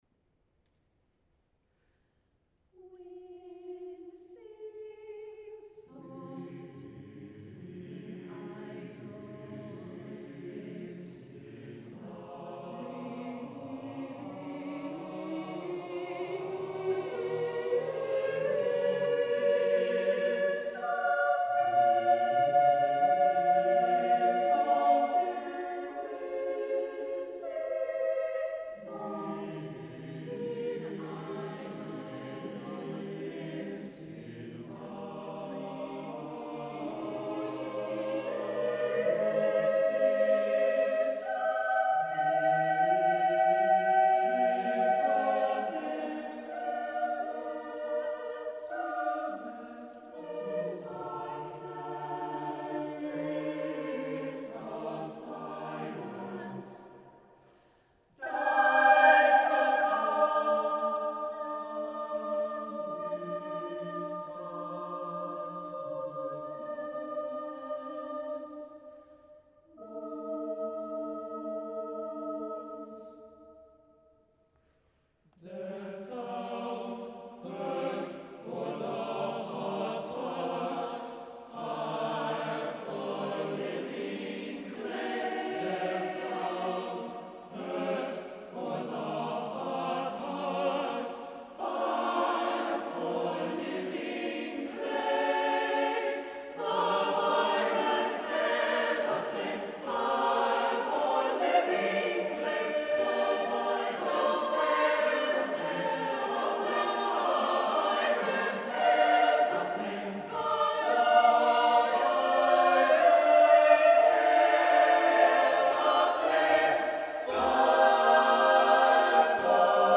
SATB Choir with Soprano Solo (from within choir)
(SATB)